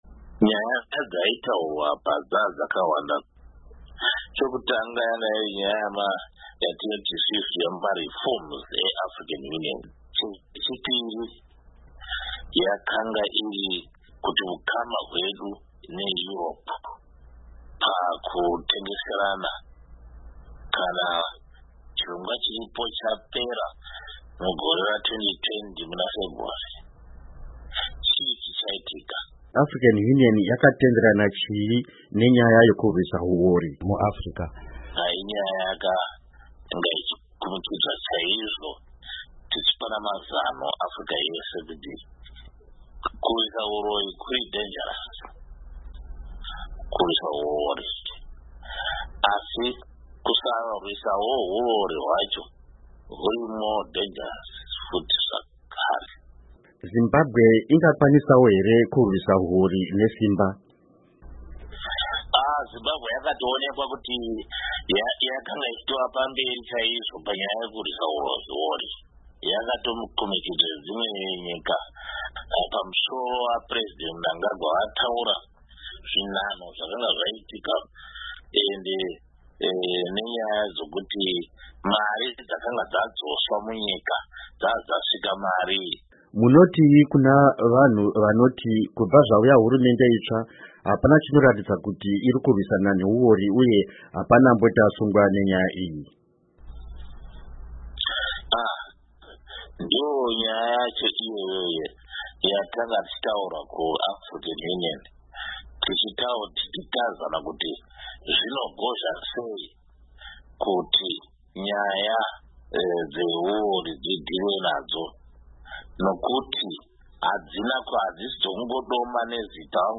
Hurukuro NaDoctor Sibusiso Moyo